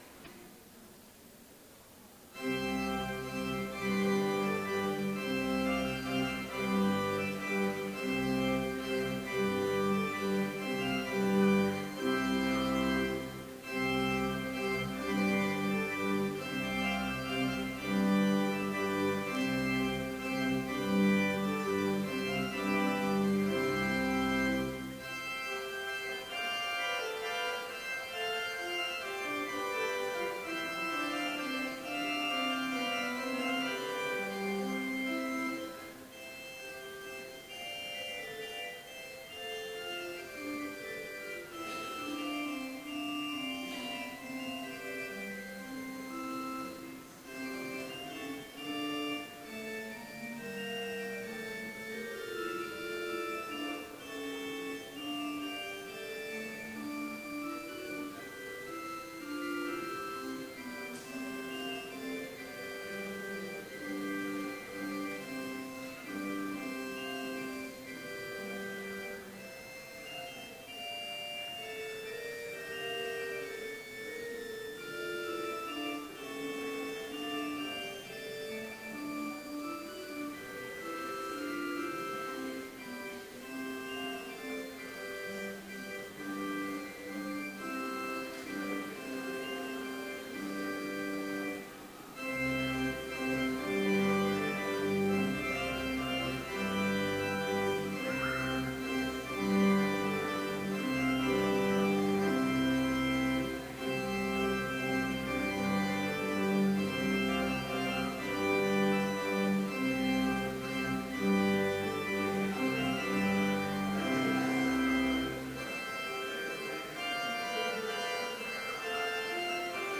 Complete service audio for Chapel - April 19, 2016